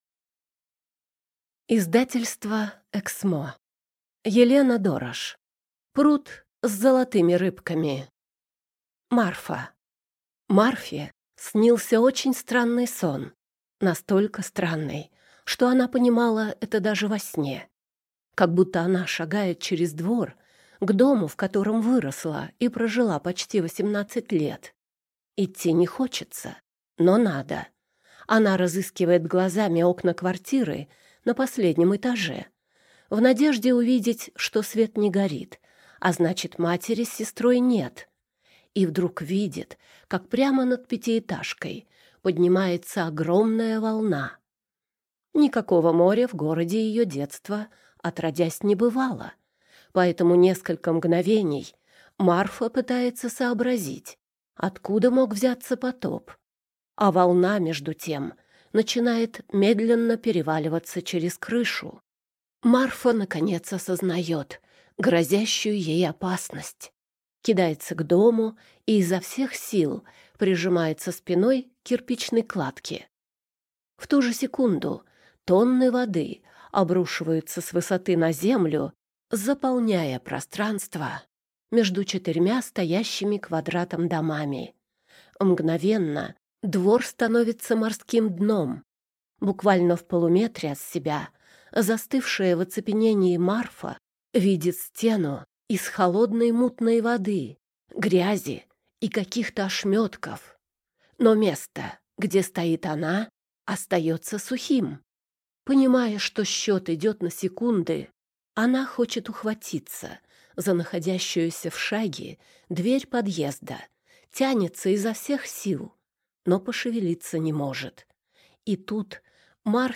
Аудиокнига Пруд с золотыми рыбками | Библиотека аудиокниг